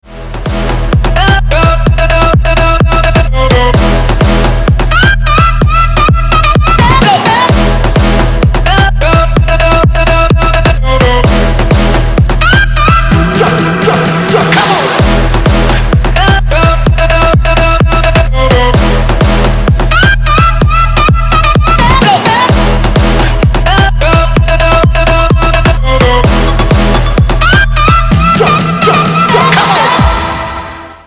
Громкие звонки, звучные рингтоны